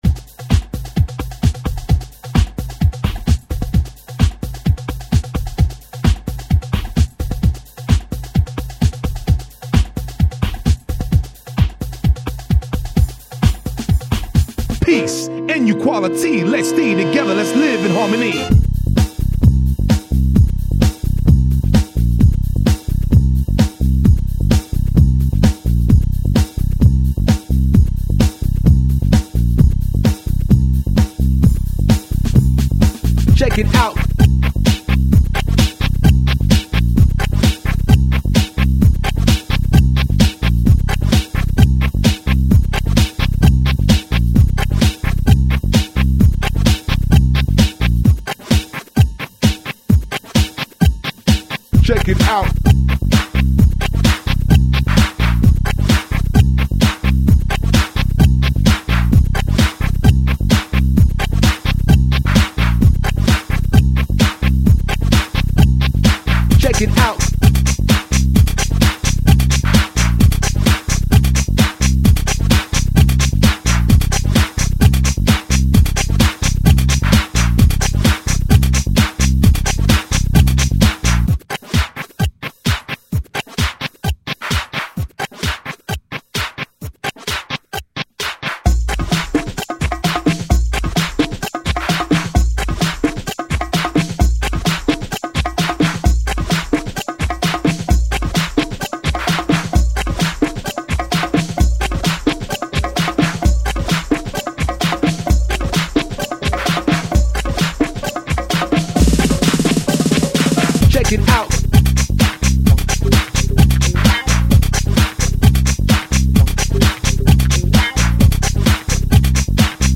2,41 MB 1998 Techno